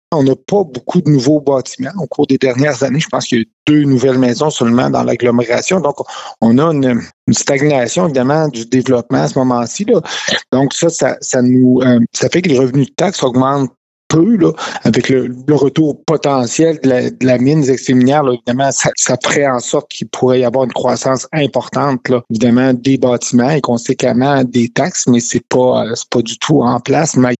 Le maire de Murdochville explique que les ressources financières de la municipalité sont limitées, pour l’instant :